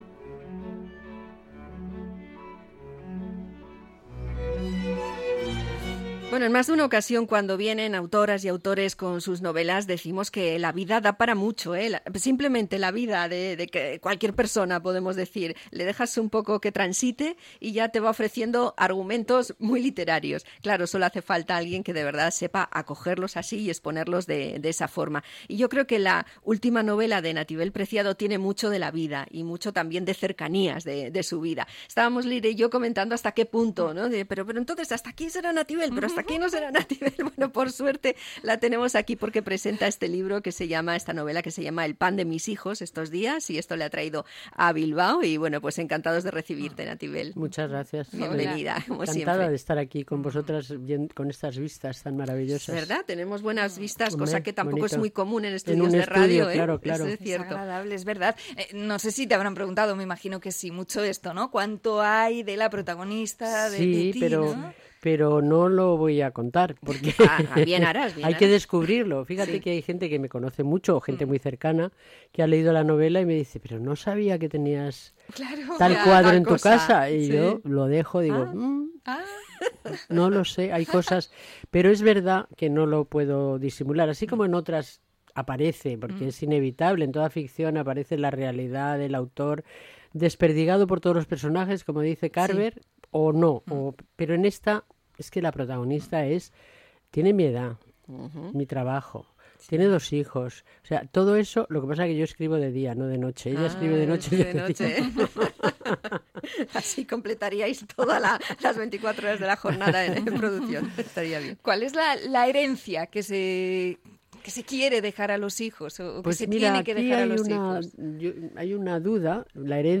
Entrevista a la periodista y escritora Nativel Preciado